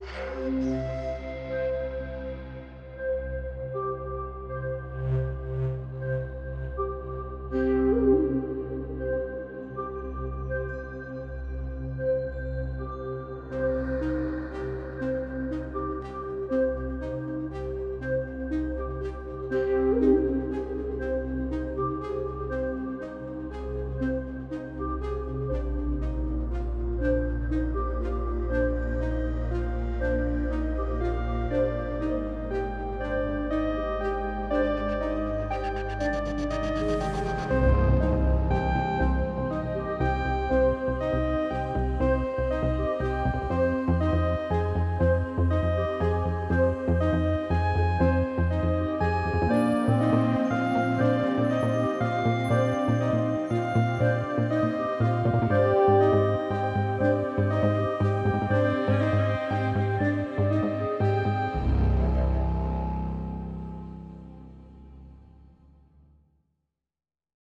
3. 合成器
Observant Sound Veiled Ashes 是一个双重采样播放器，擅长于创造多变的氛围和调制。